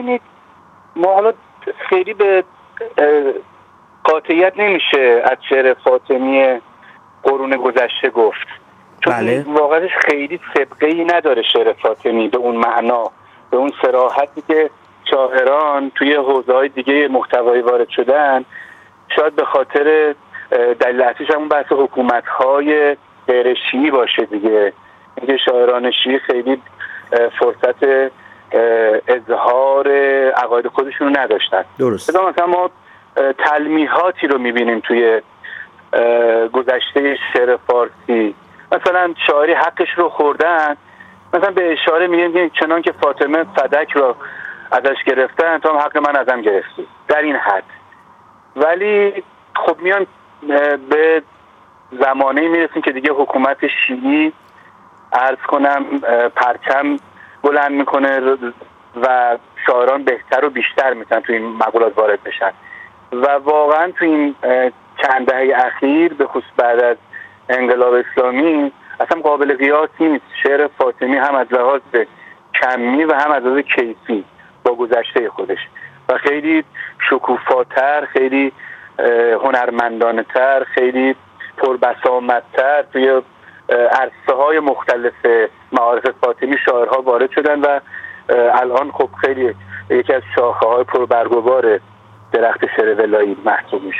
گفت‌وگو با ایکنا